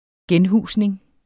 Udtale [ ˈgεnˌhuˀsneŋ ]